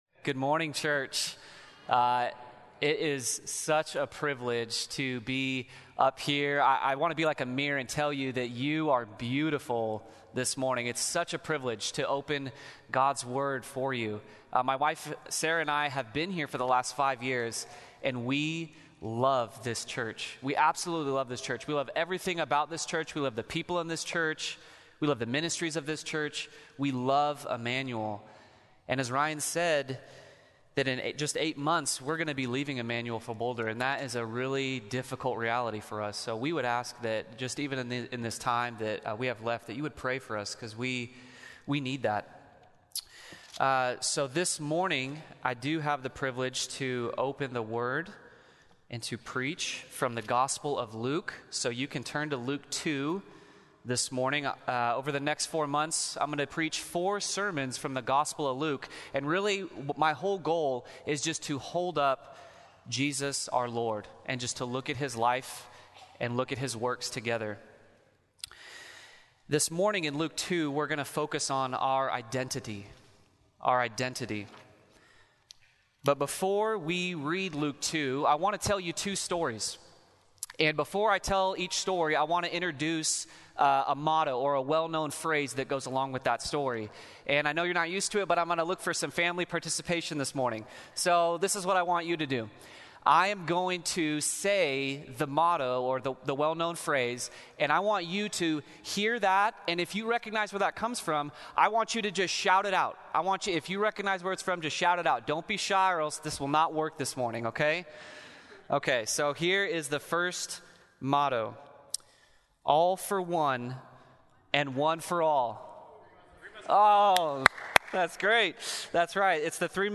Sermons | Immanuel Baptist Church